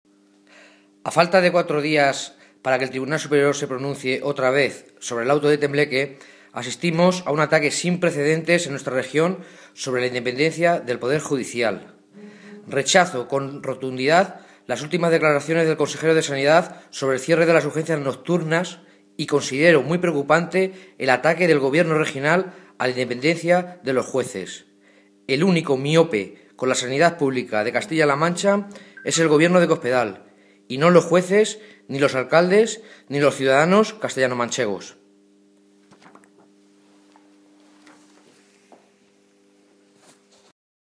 Cortes de audio de la rueda de prensa
Audio alcalde de Tembleque
Alcalde_de_Tembleque_critica_ultimas_declaraciones_Echaniz.mp3